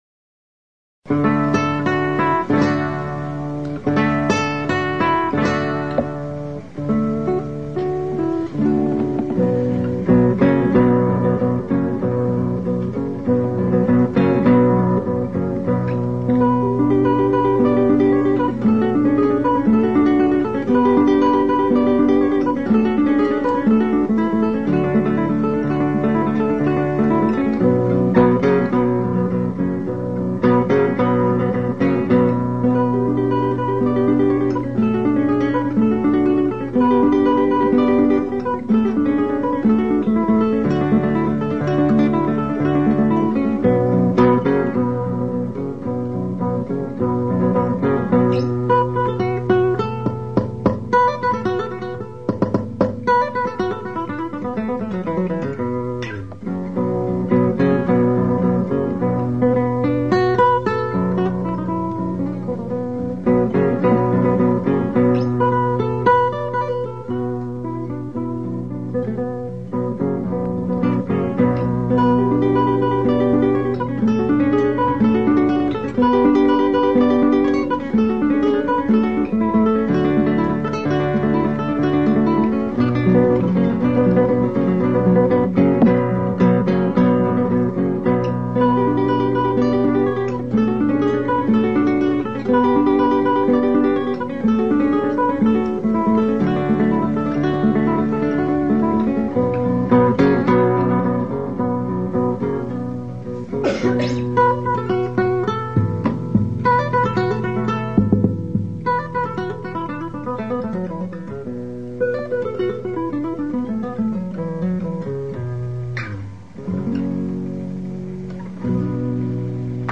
Kresge Little Theater MIT Cambridge, Massachusetts USA